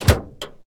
train-door-open-2.ogg